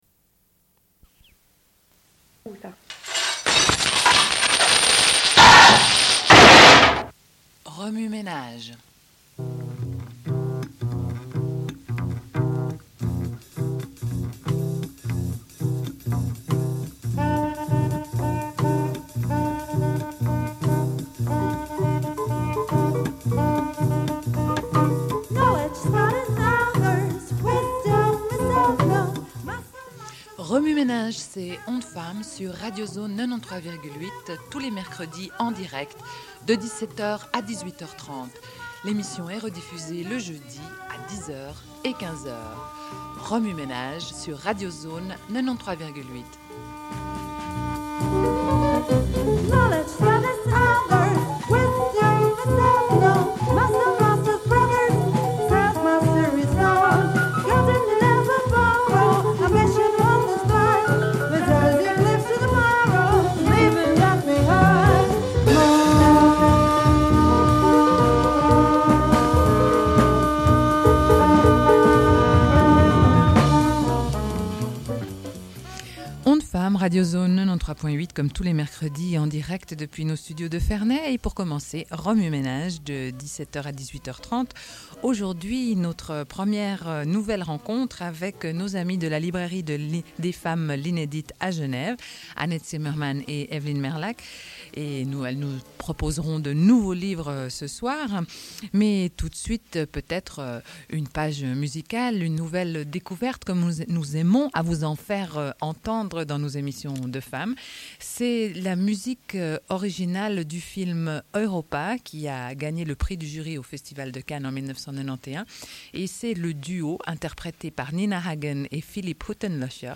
Une cassette audio, face A31:43